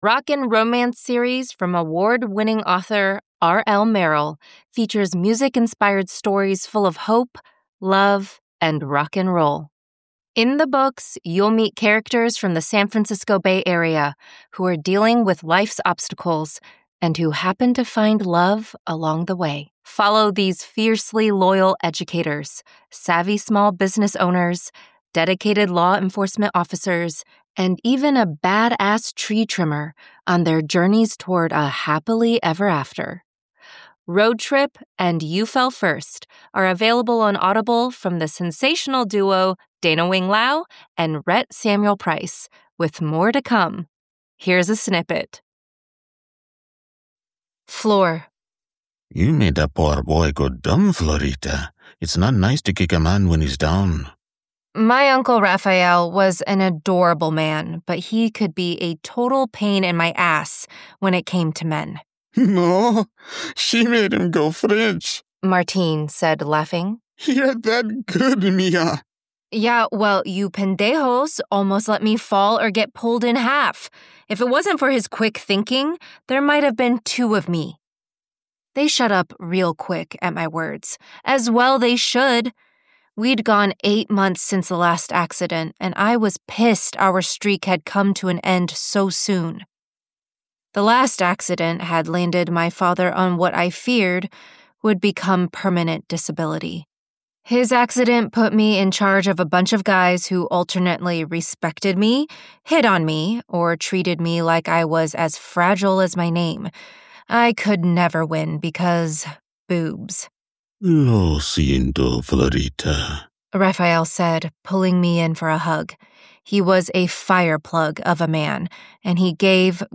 YouFellFirstPromo_Mastered.mp3